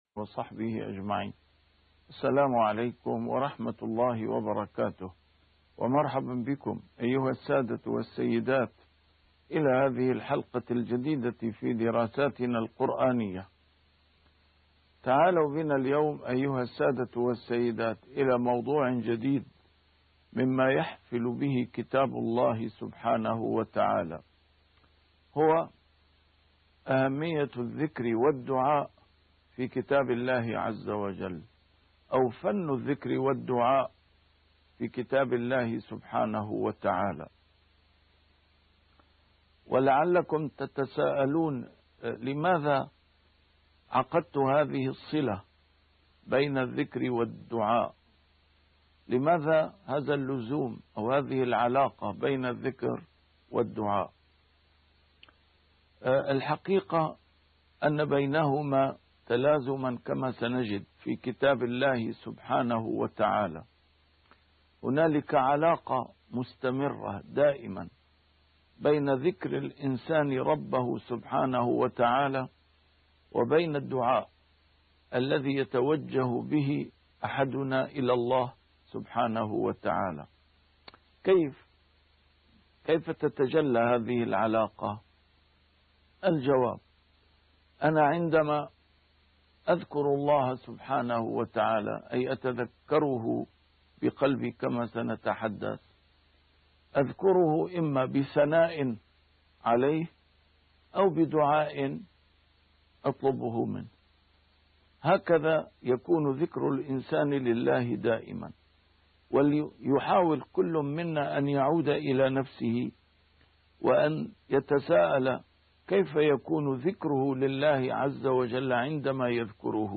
محاضرات متفرقة في مناسبات مختلفة - A MARTYR SCHOLAR: IMAM MUHAMMAD SAEED RAMADAN AL-BOUTI - الدروس العلمية - الذكر في القرآن الكريم 2 -